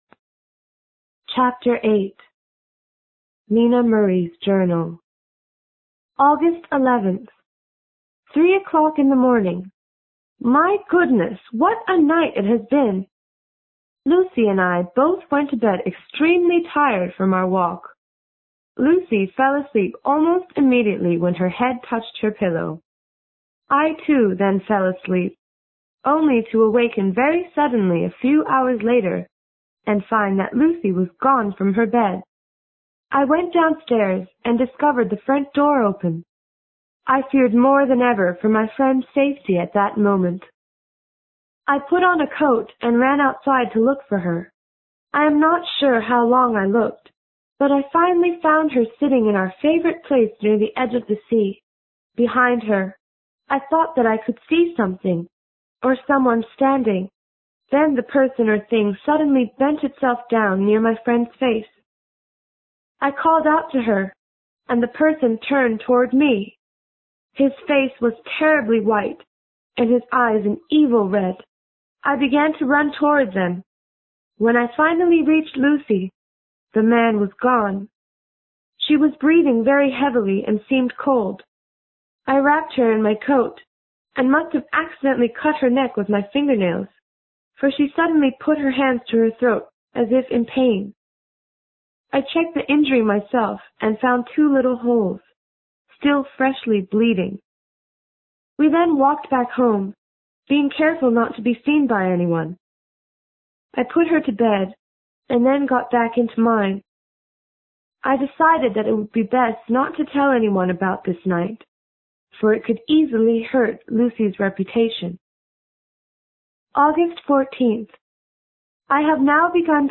有声名著之吸血鬼 Chapter8 听力文件下载—在线英语听力室